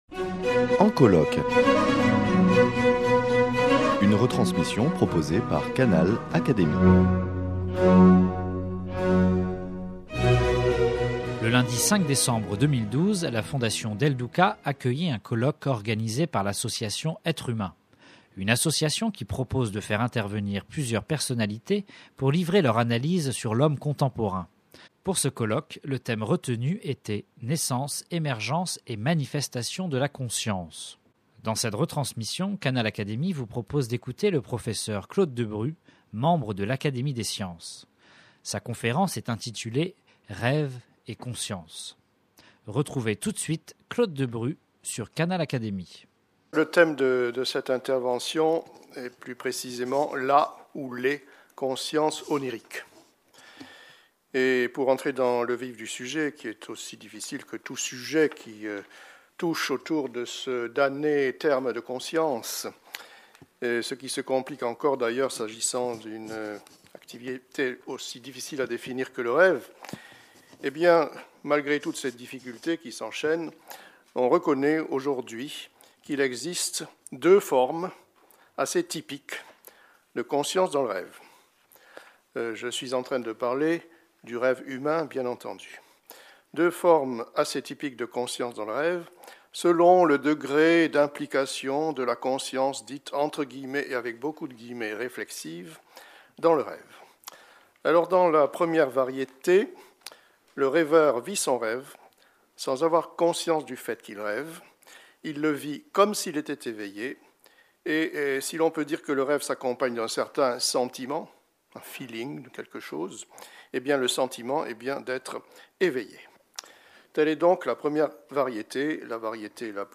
Le lundi 5 décembre 2012, la Fondation Simone et Cino del Duca accueillait un nouveau colloque de l’association "Être humain", cette fois consacré à "la conscience".